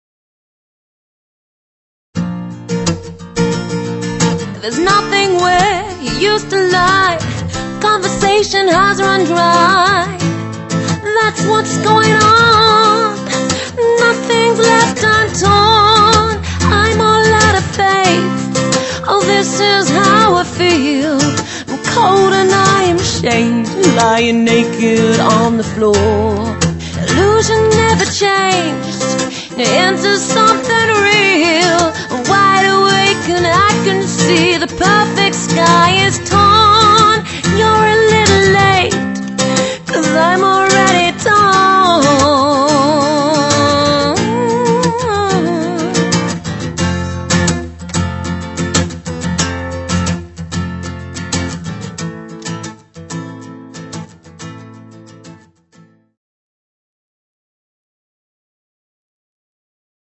singing and playing acoustic guitar as a solo performer